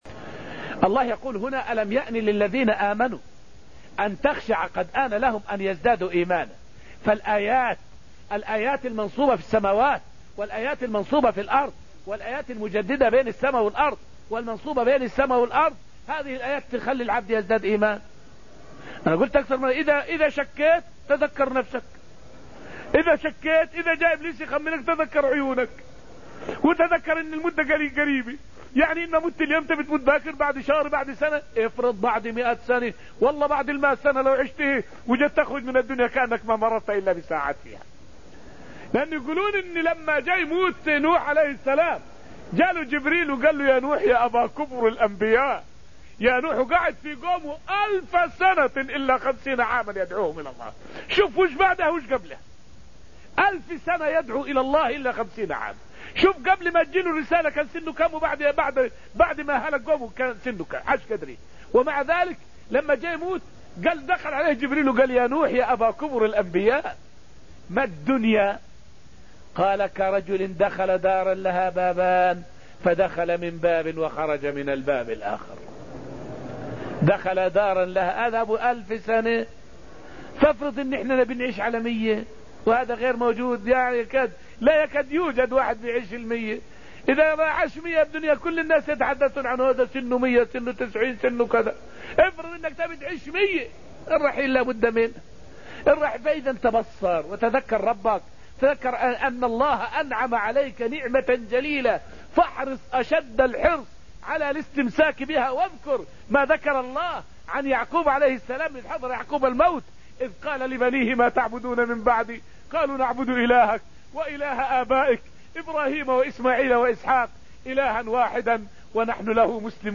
فائدة من الدرس الثامن عشر من دروس تفسير سورة الحديد والتي ألقيت في المسجد النبوي الشريف حول تذكر الموت.